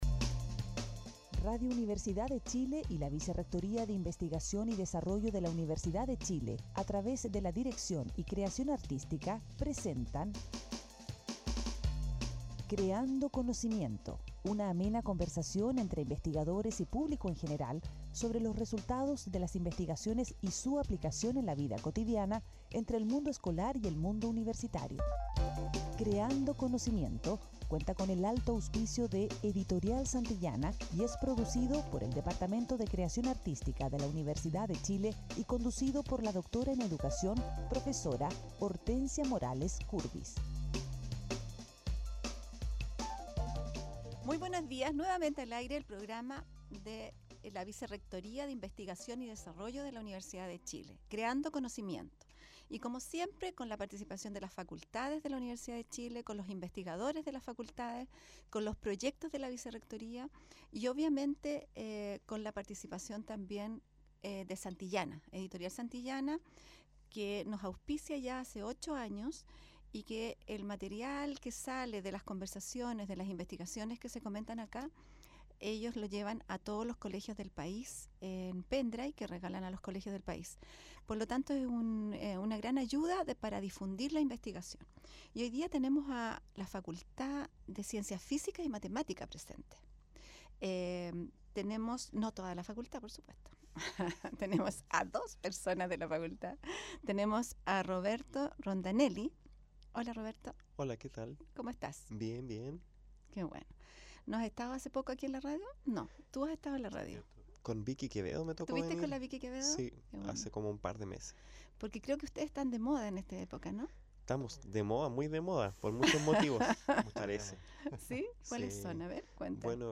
Escucha la entrevista en Radio U. de Chile.